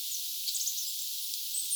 erikoista ääntä,
ehkäpä nuoren sinitiaisen ääni
erikoista_aanta_mahd_nuori_sinitiainen_ehkapa.mp3